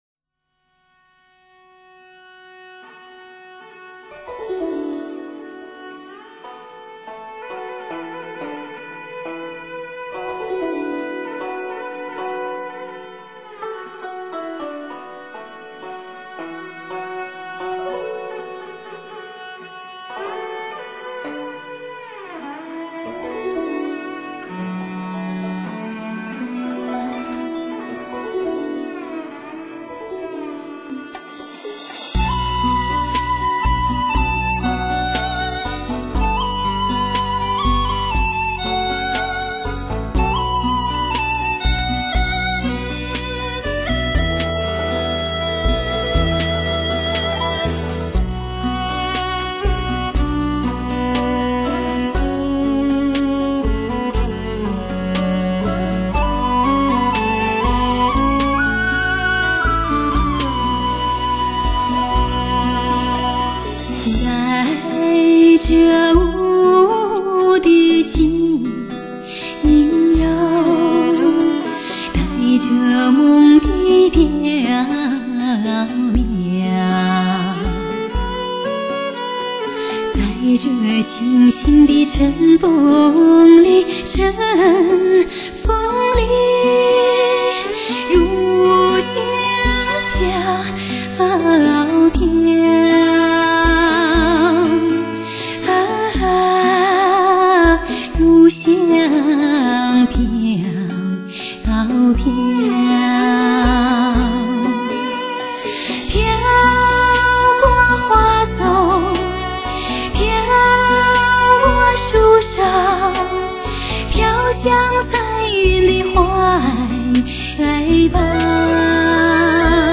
歌声娇柔、温馨、祥和、甜美、快乐、婉转、真诚没有虚假的做作，让这静谧的音乐荡漾过你的心扉，让你在美丽和温情中更加安祥！